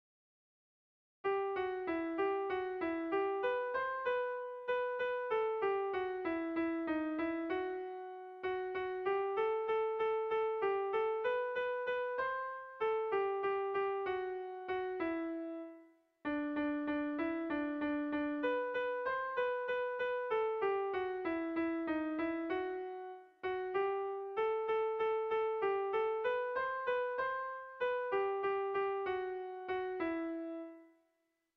Air de bertsos - Voir fiche   Pour savoir plus sur cette section
Irrizkoa
Zortziko handia (hg) / Lau puntuko handia (ip)
ABDB